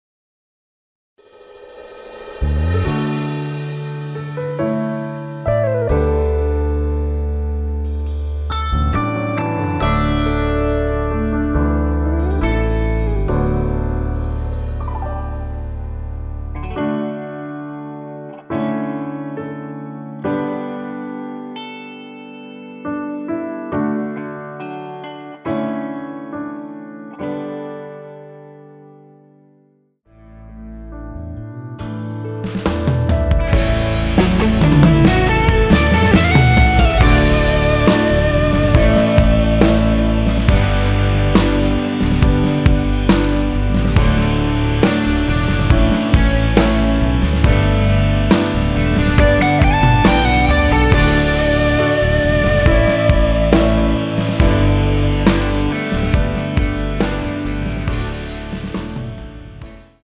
원키에서(-2)내린 MR입니다.
앞부분30초, 뒷부분30초씩 편집해서 올려 드리고 있습니다.
중간에 음이 끈어지고 다시 나오는 이유는
곡명 옆 (-1)은 반음 내림, (+1)은 반음 올림 입니다.